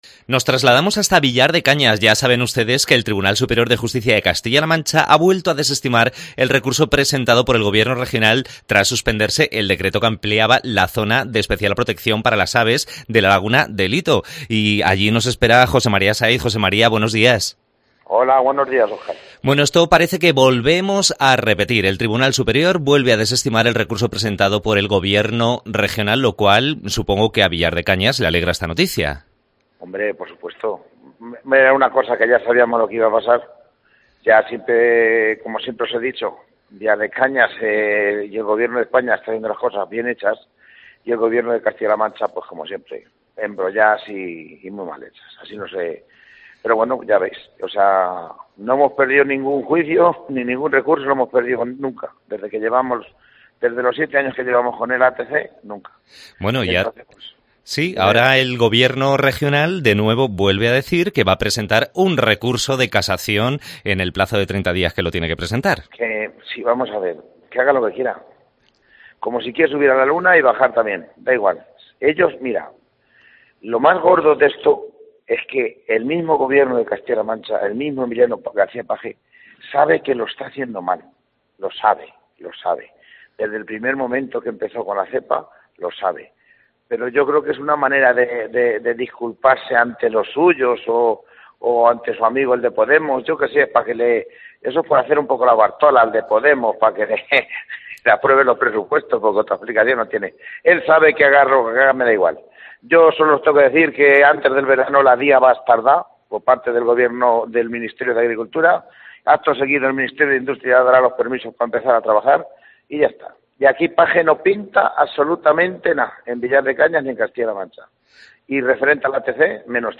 Entrevista realizada al Alcalde de Villar de Cañas, Jose María Sáiz, con motivo de la de la decision del TSJCLM de desestimar el recurso presentado por la JCCM.